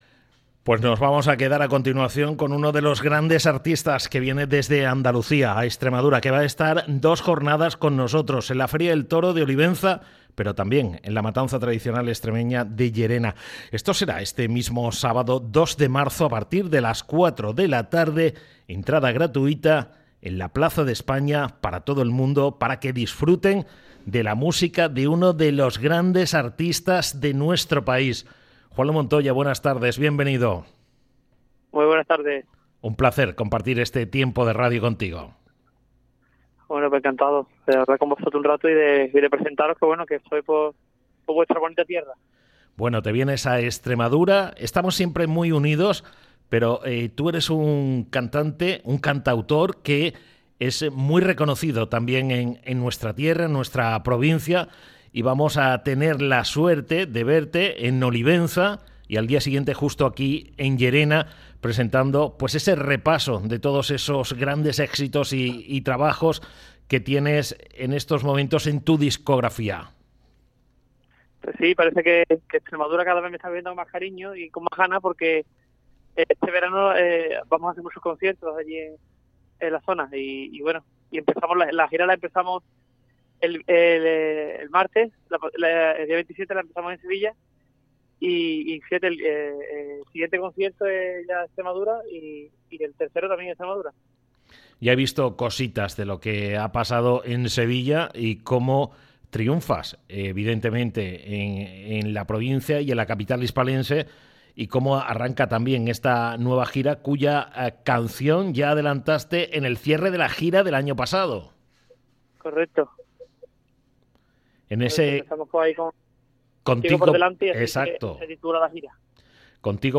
ha pasado por los micrófonos de Onda Cero para adelantar como será su concierto que ofrecerá mañana en Llerena.